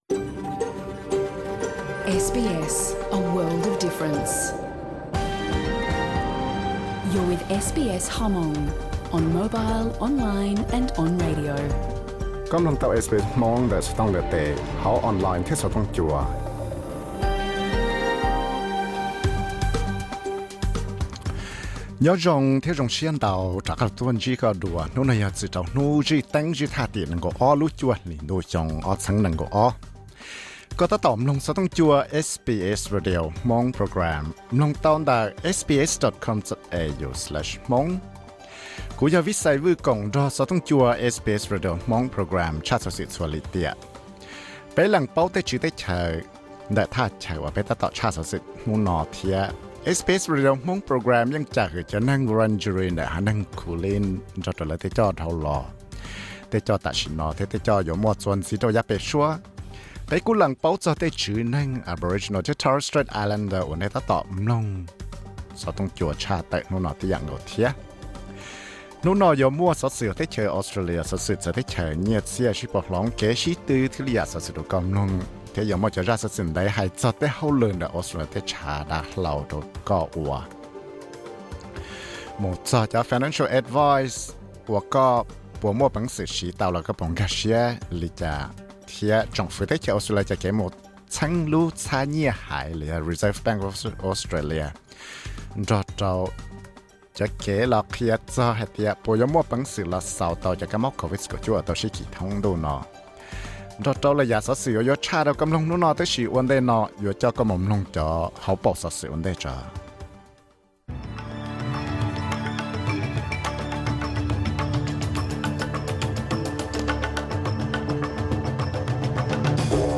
Program xov xwm tshaj tawm rau hnub zwj Teeb (Thursday news program 22.09.2022):